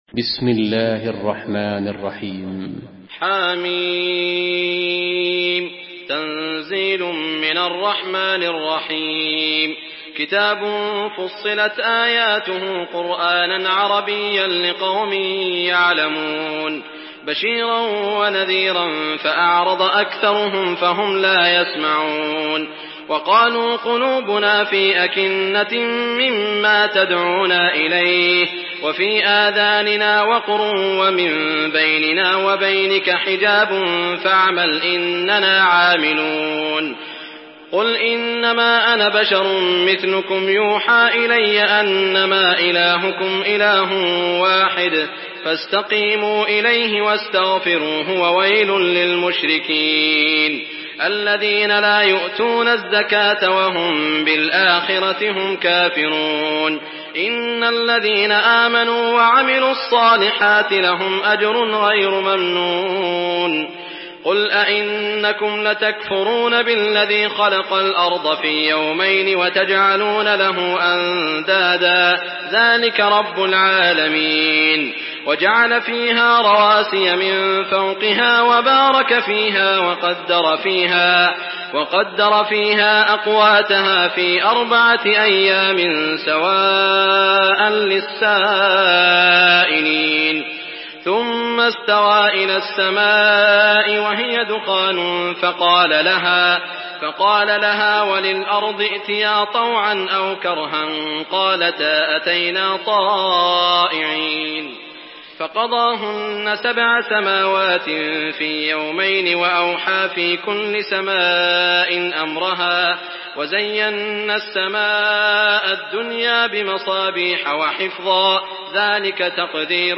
Surah Fussilat MP3 in the Voice of Saud Al Shuraim in Hafs Narration
Surah Fussilat MP3 by Saud Al Shuraim in Hafs An Asim narration.